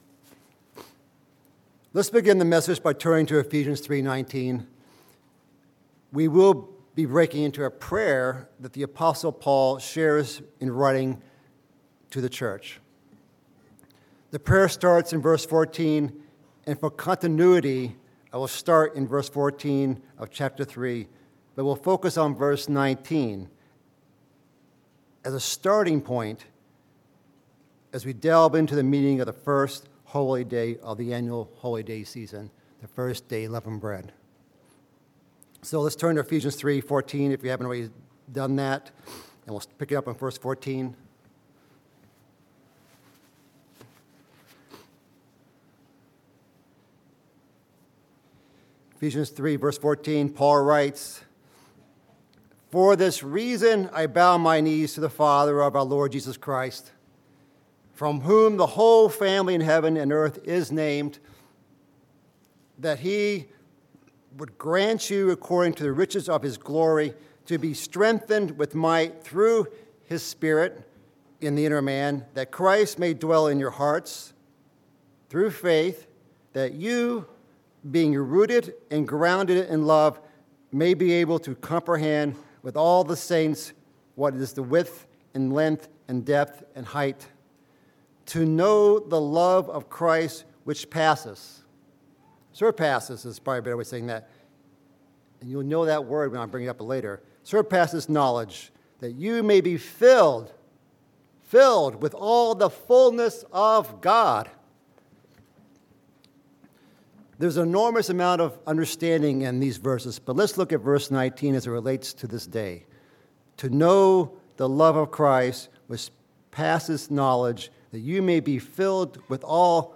Sermons
Given in Chicago, IL Northwest Indiana